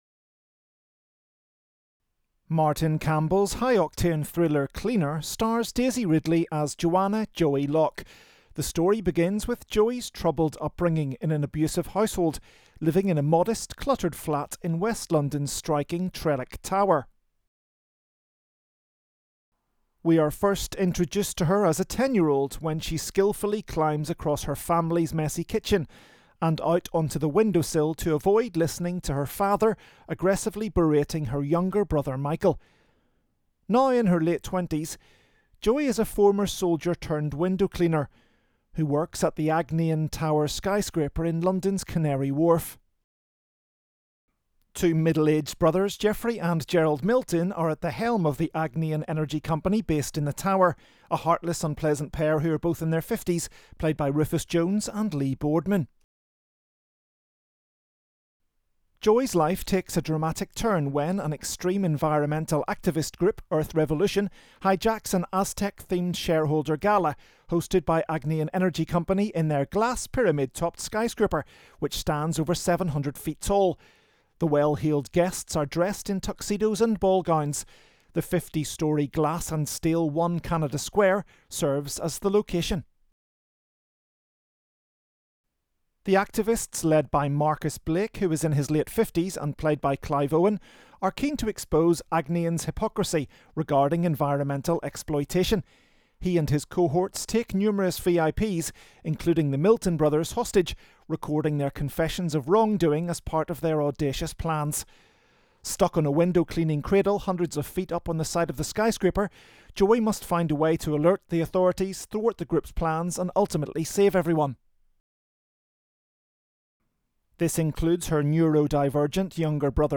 Cleaner - AD Introduction
Cleaner_AD_Intro.wav